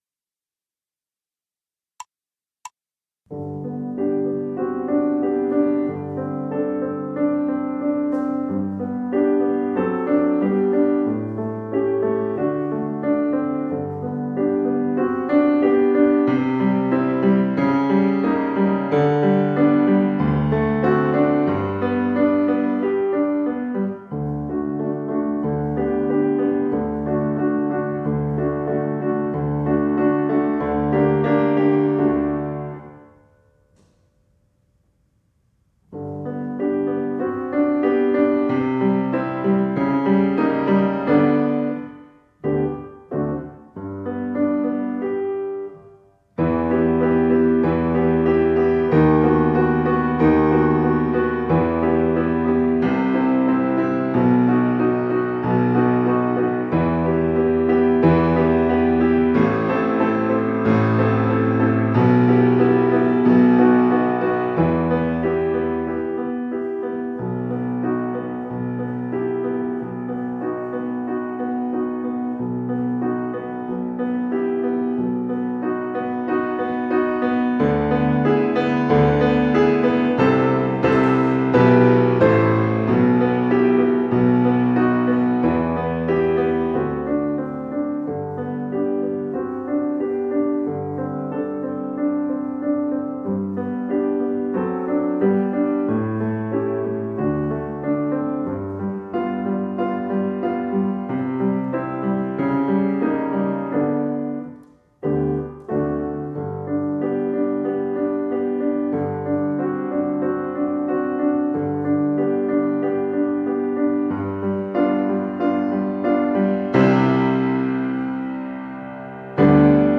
A REAL PIANO ACCOMPANIMENT !
Grant Recital Hall
Steinway concert grand piano
• Greater dynamic range